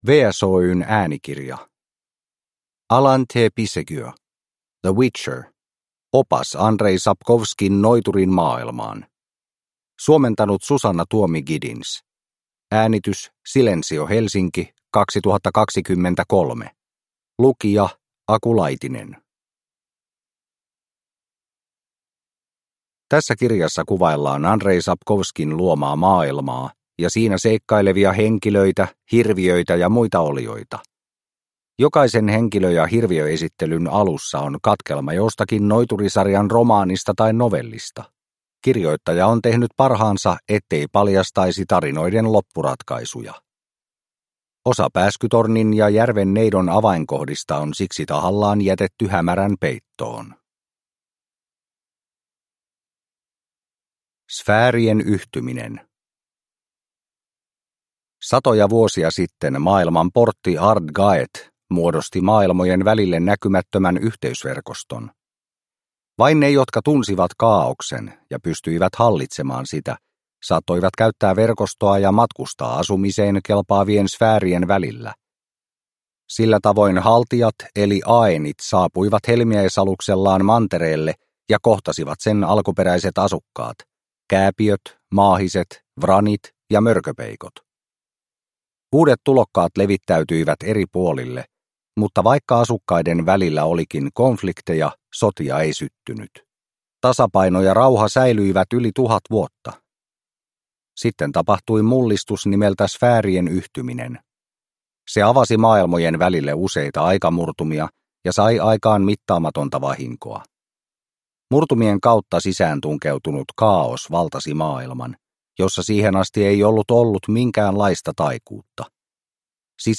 The Witcher – Opas Andrzej Sapkowskin Noiturin maailmaan – Ljudbok – Laddas ner